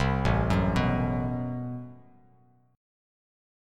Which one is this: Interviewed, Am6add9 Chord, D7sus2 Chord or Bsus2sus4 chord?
Am6add9 Chord